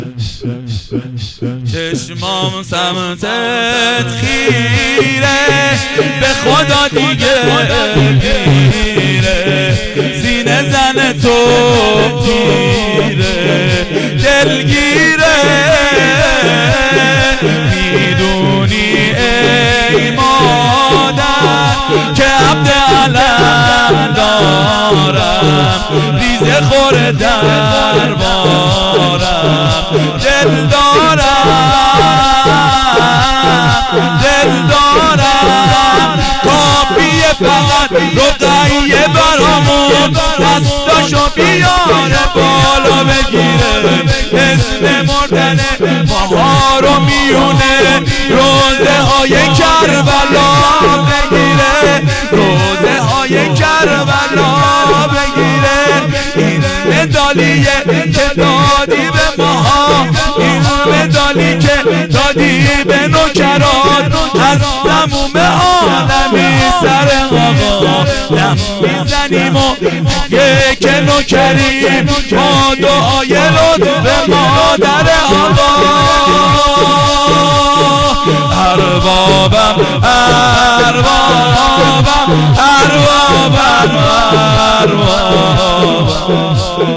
شور
شهادت حضرت زهرا 1399 خادیمن فاطمی
هیئت خادمین فاطمی